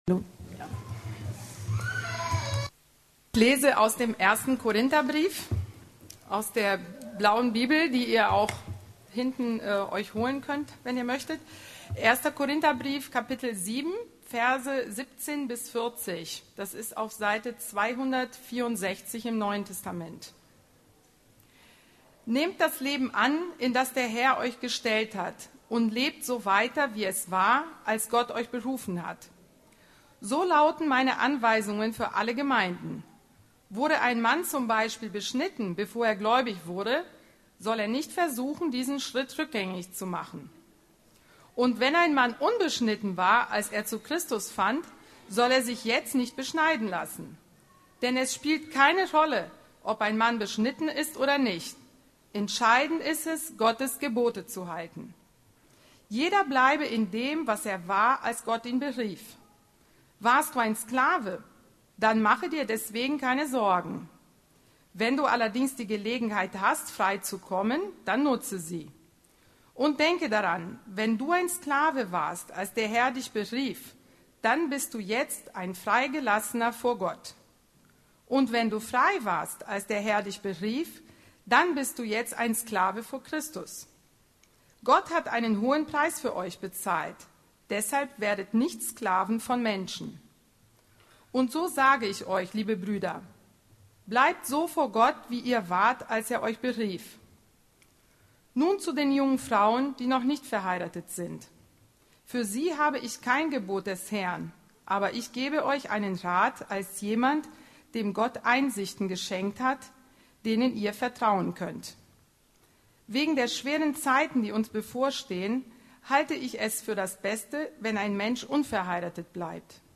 Heiraten oder Single bleiben – was ist besser? ~ Predigten der LUKAS GEMEINDE Podcast